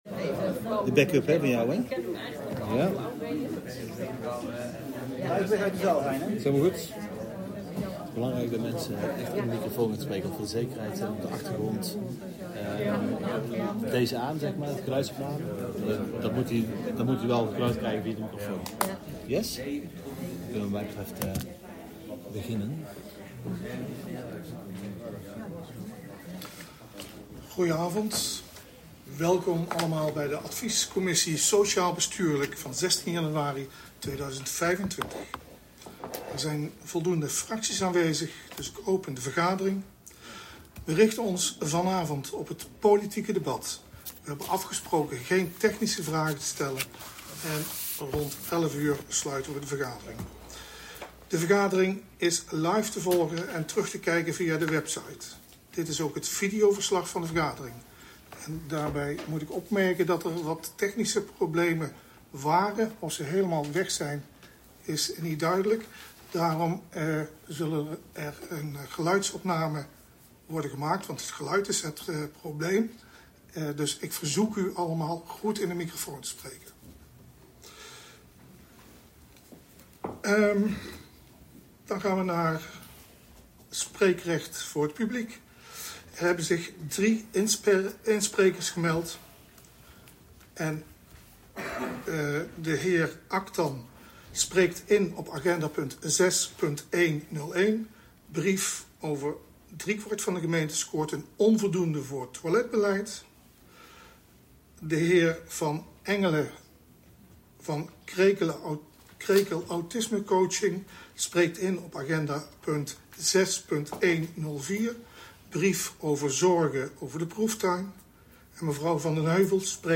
Wegens een technische storing is er geen geluid bij de uitzending van deze commissievergadering. Een geluidsopname is toegevoegd.